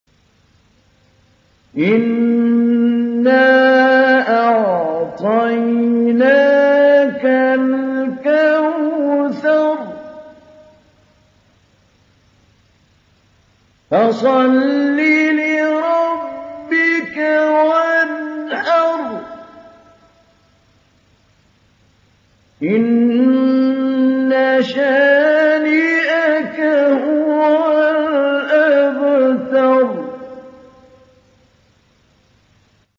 ডাউনলোড সূরা আল-কাউসার Mahmoud Ali Albanna Mujawwad